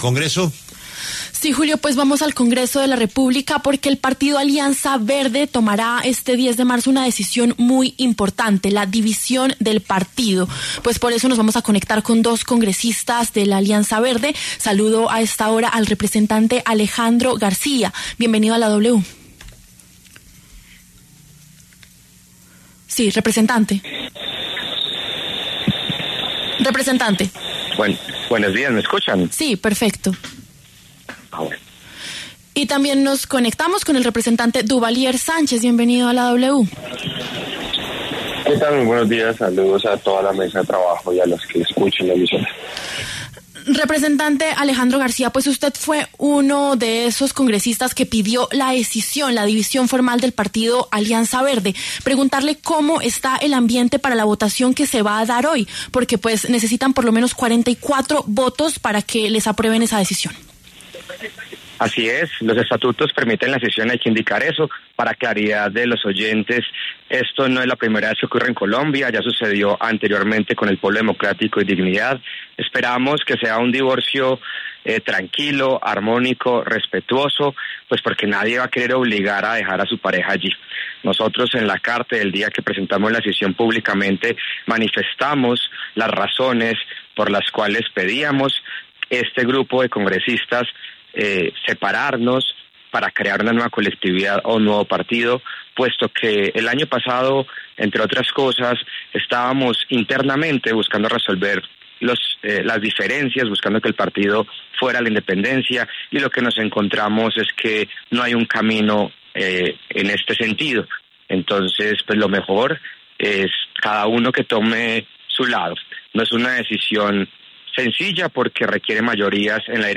El representante Alejandro García, uno de los seis congresistas que pidieron la división y Duvalier Sánchez, quien se opone a la solicitud, debatieron en los micrófonos de La W.